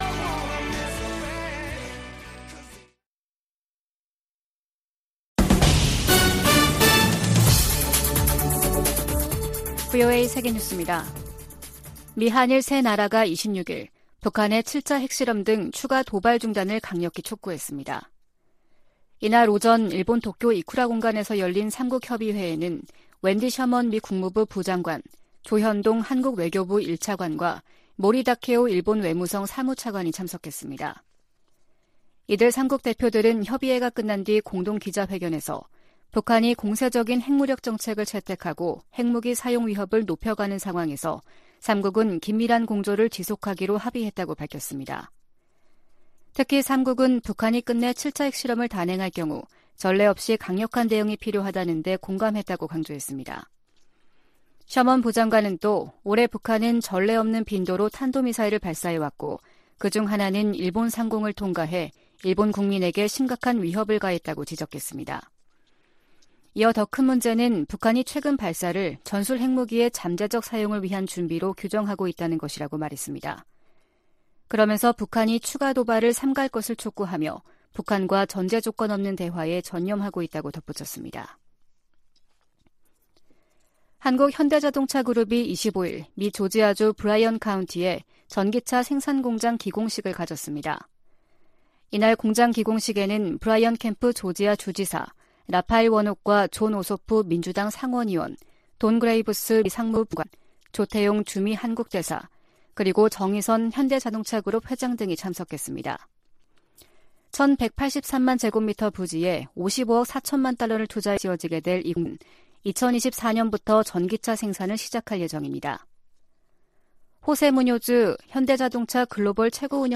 VOA 한국어 아침 뉴스 프로그램 '워싱턴 뉴스 광장' 2022년 10월 27일 방송입니다. 미국·한국·일본의 외교차관이 26일 도쿄에서 협의회를 열고 핵실험 등 북한의 추가 도발 중단을 강력히 촉구했습니다. 미 국무부는 북한이 7차 핵실험을 강행할 경우 대가를 치를 것이라고 경고한 사실을 다시 강조했습니다. 미 국방부는 한반도 전술핵 재배치와 관련한 질문에 강력한 억지력 보장을 위해 한국·일본 등 동맹과 긴밀히 협력할 것이라고 밝혔습니다.